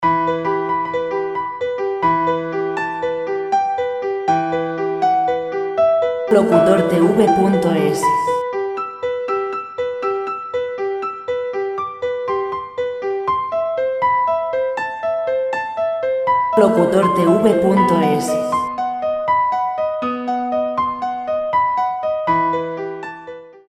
musica piano sin copyright